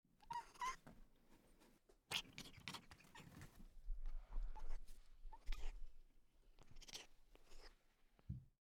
Звук новорожденного котенка, который пока не умеет мяукать